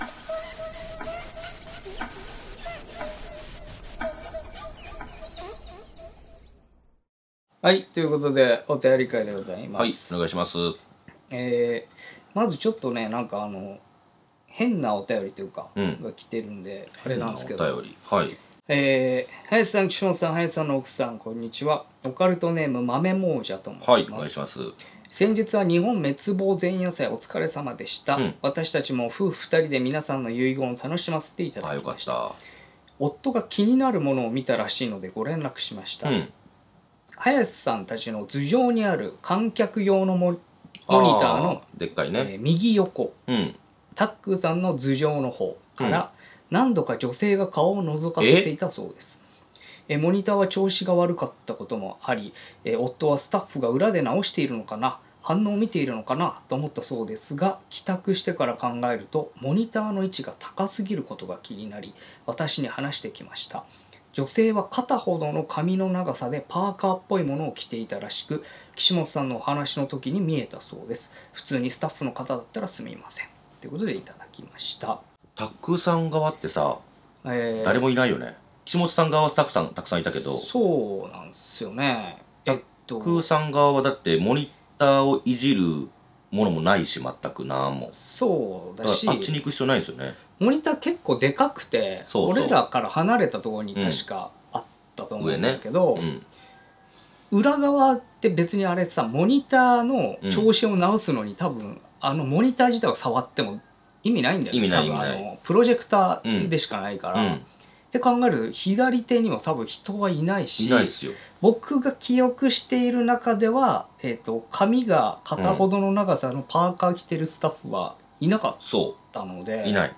若手放送作家の２人が都市伝説や日常に忍び寄るオカルト又は眉唾な噂話を独自の目線で切りお送りしていく番組です。